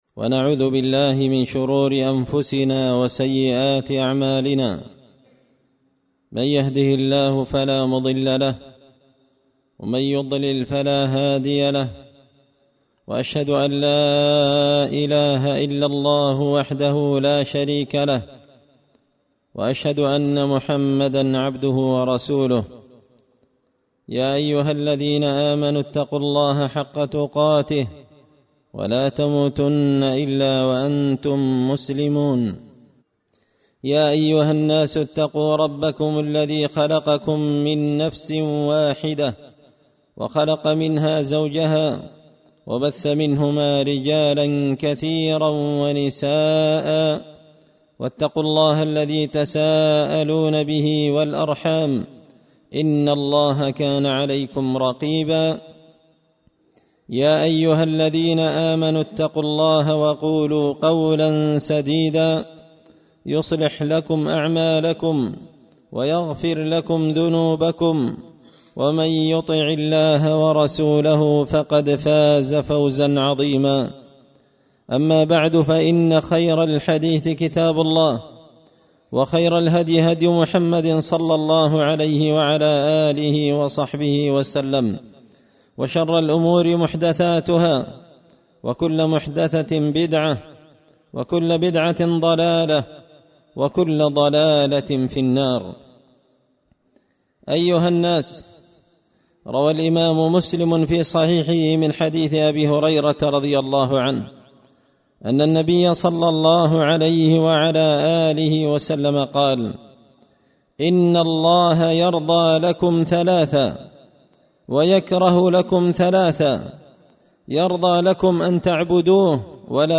خطبة جمعة بعنوان – التنازع سبب الفشل
دار الحديث بمسجد الفرقان ـ قشن ـ المهرة ـ اليمن